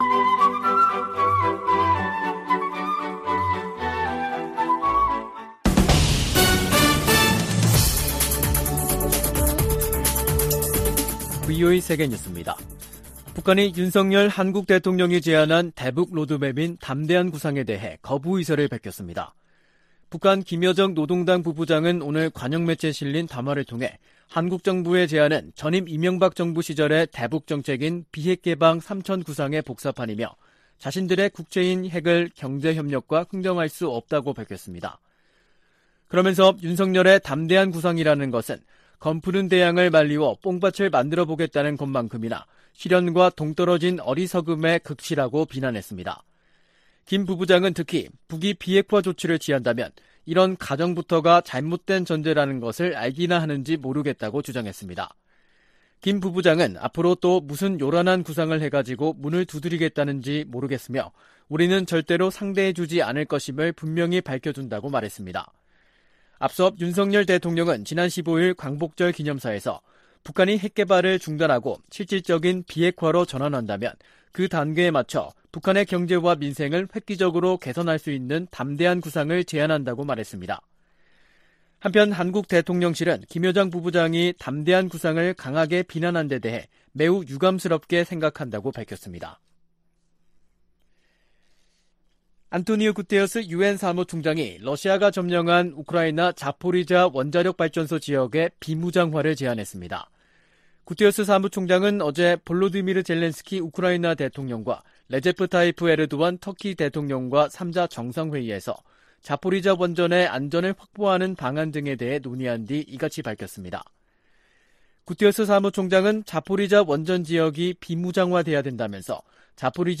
세계 뉴스와 함께 미국의 모든 것을 소개하는 '생방송 여기는 워싱턴입니다', 2022년 8월 19일 저녁 방송입니다. '지구촌 오늘'에서는 VOA 우크라이나어 서비스가 올렉시 레즈니코우 우크라이나 국방장관과 인터뷰한 내용 전해드리고, '아메리카 나우'에서는 도널드 트럼프 전 대통령 최측근이 탈세 혐의 등 유죄를 인정한 이야기 살펴보겠습니다.